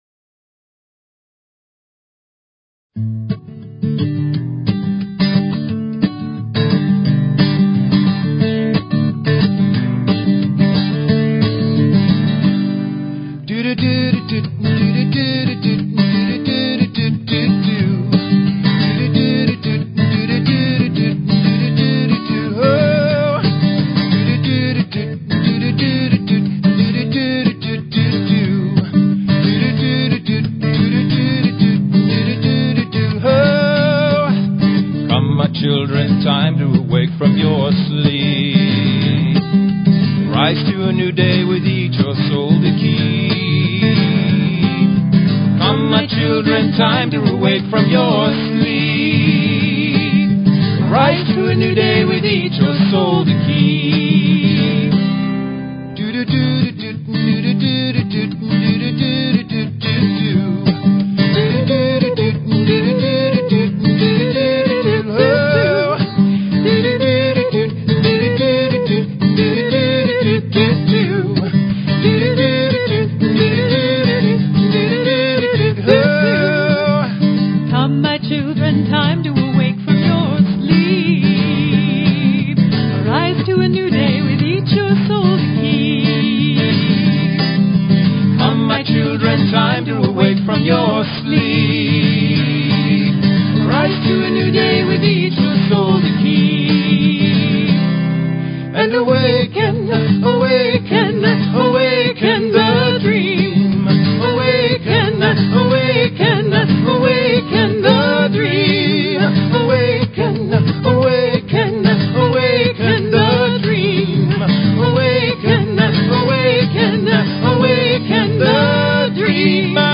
Talk Show Episode, Audio Podcast, Enlightened_Medicine and Courtesy of BBS Radio on , show guests , about , categorized as
This time, in the season of over-indulgence, we'll be getting to the nitty-gritty weight dilemma! Call-ins are welcome, so please give us a call and find out if your problem could be "all in your head" (neurotransmitters).